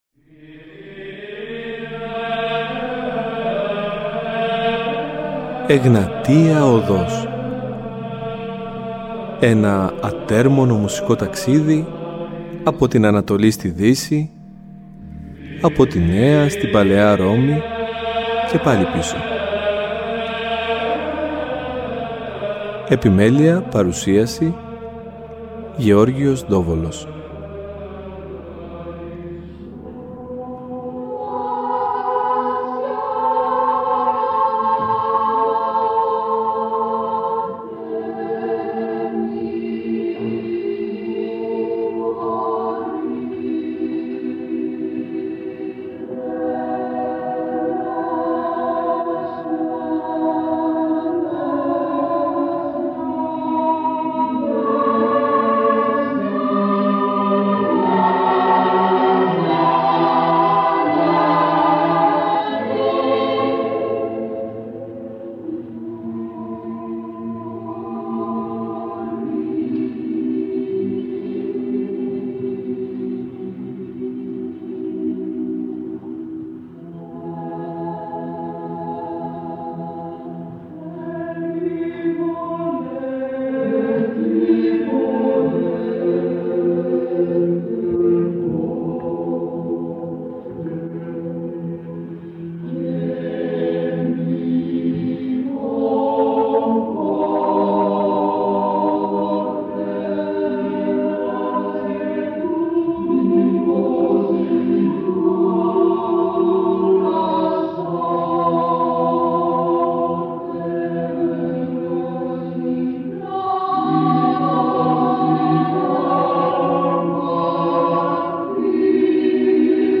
Μία χορωδία που διακρίθηκε μεταξύ άλλων στο χώρο της πολυφωνικής εκκλησιαστικής μουσικής. Τόσο η φρεσκάδα των παιδικών φωνών όσο και ο άρτιος ήχος αλλά κυρίως το μεράκι ήταν αυτά που την ξεχώρισαν. Κατάφερε να συνδυάσει το Βυζαντινό ύφος της Θεσσαλονίκης με την Ευρωπαϊκή τετραφωνία κάτι που εκ των πραγμάτων έχει μείνει ιστορικό.